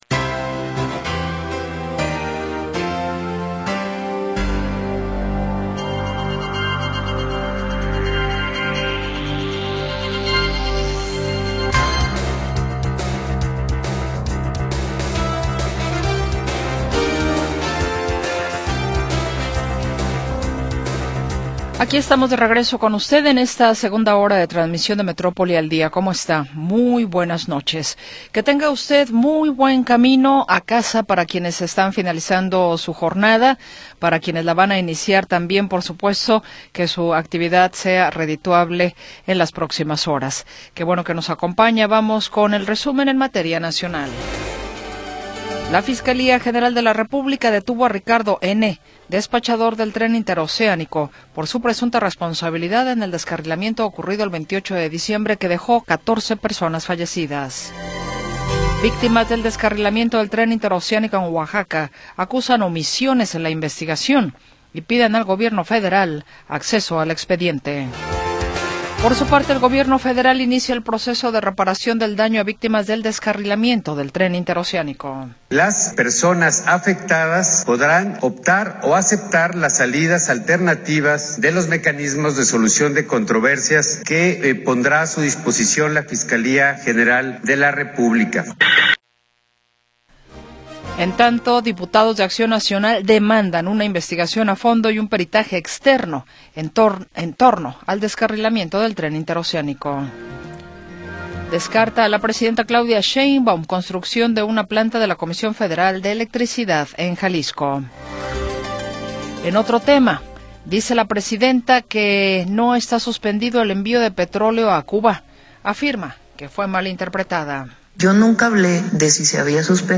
Segunda hora del programa transmitido el 28 de Enero de 2026.